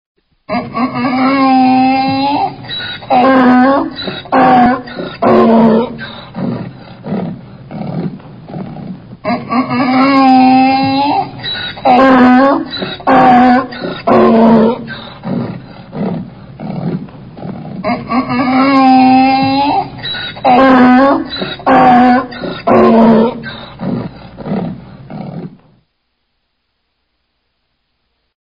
Рычание упрямого осла